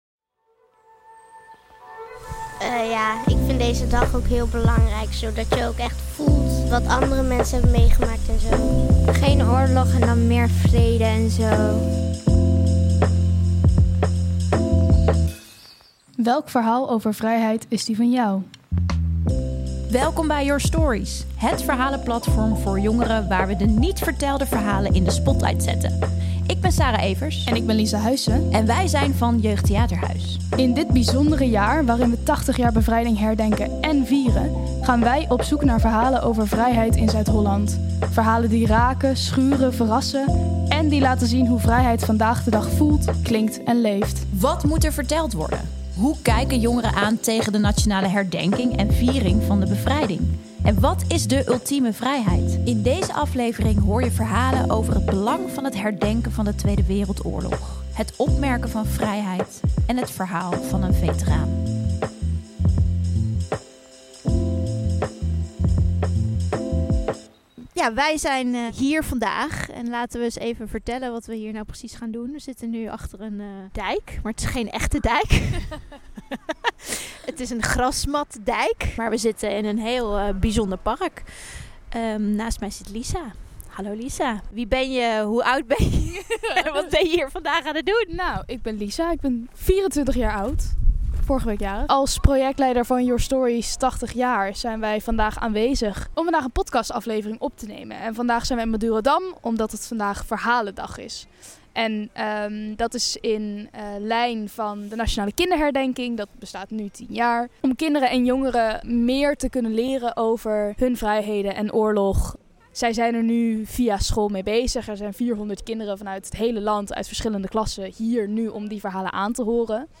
In deze aflevering hoor je de verhalen van jongeren tijdens de verhalendag van de Nationale Kinderherdenking. Ze vertellen over het belang van het herdenken van de tweede wereldoorlog, het opmerken van vrijheid en hoor je het verhaal van een veteraan.